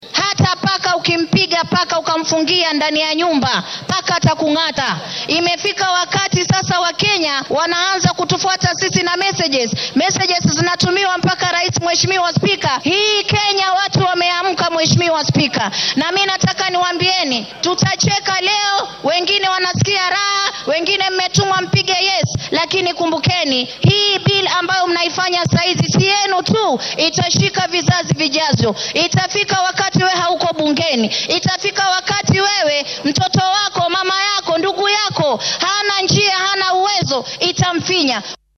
Wakiilka haweenka ee ismaamulka Mombasa Zamzam Maxamad ayaa si adag uga hadashay hindise sharciyeedka baarlamaanka hor yaalla.
Wakiilka-haweenka-Mombasa.mp3